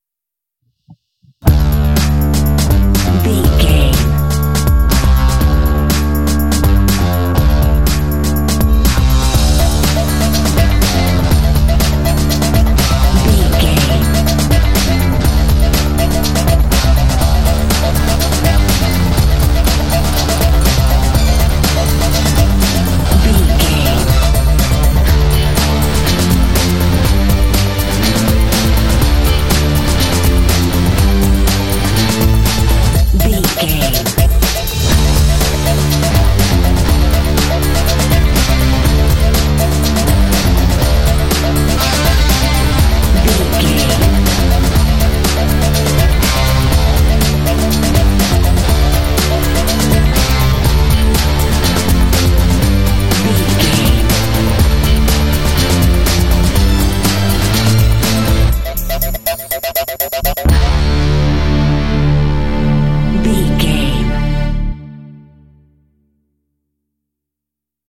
Uplifting
Dorian
D
funky
groovy
driving
synthesiser
drums
electric guitar
bass guitar
strings
electronica
cinematic